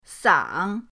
sang3.mp3